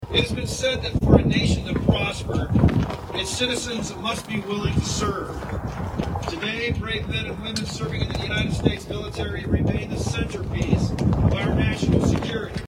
Veterans Affairs Director gives keynote speech at Veteran’s Day ceremony
(Radio Iowa) – The new executive director of the Iowa Department of Veterans Affairs gave the keynote speech during a cold, windy Veterans Day ceremony today (Friday) at the Iowa Veterans Cemetery near Van Meter.
Iowa Veterans Affairs Director Todd Jacobus